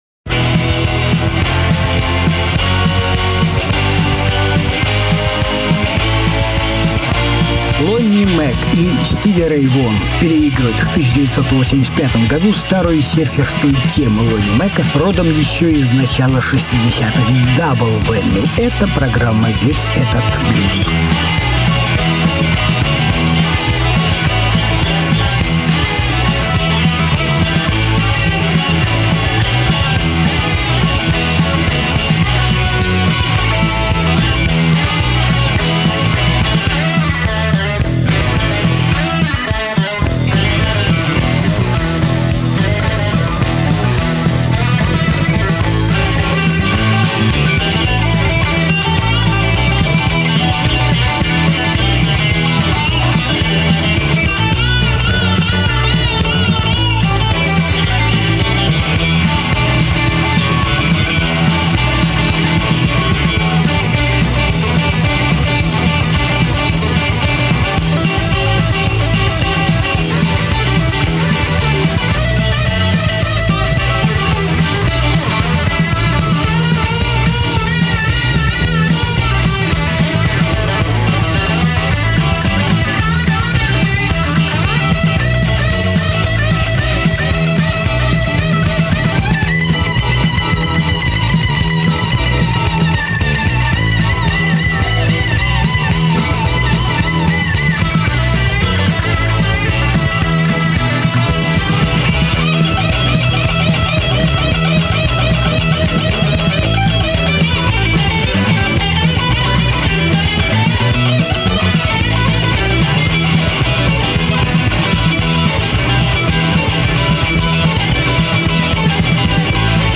Блюзы и блюзики